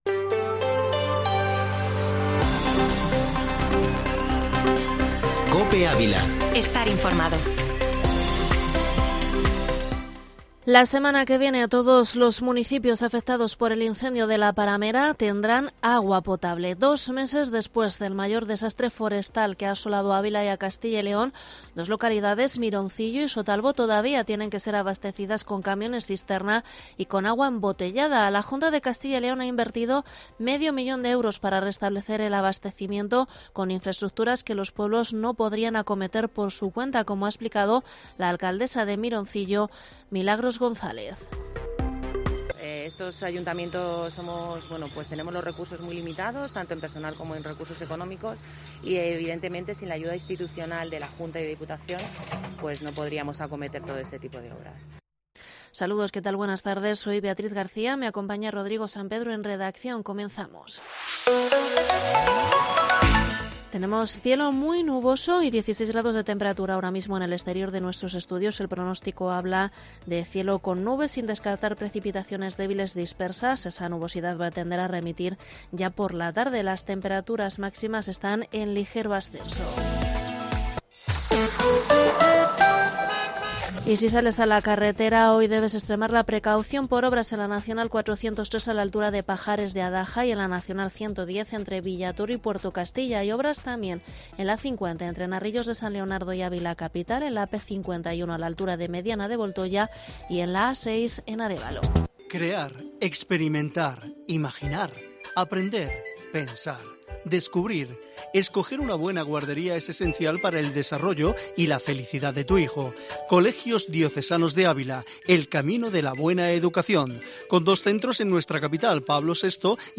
informativo Mediodía COPE ÁVILA 05/10/2021